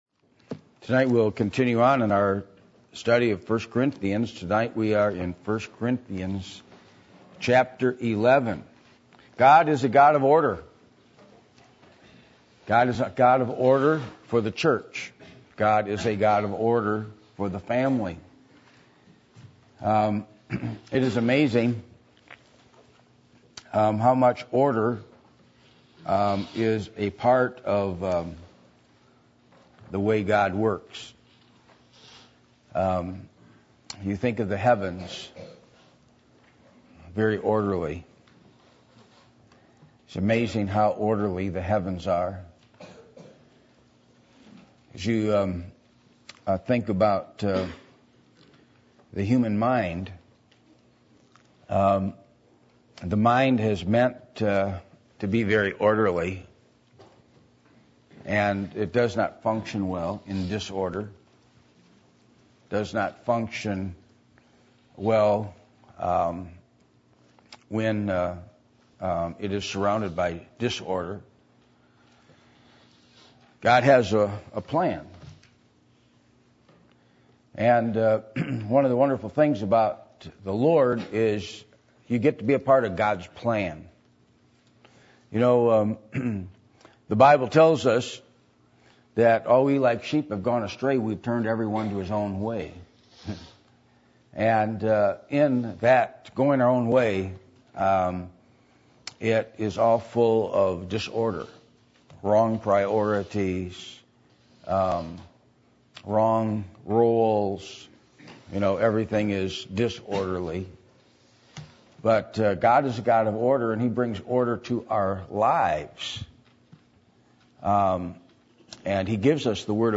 Passage: 1 Corinthians 11:1-9 Service Type: Sunday Evening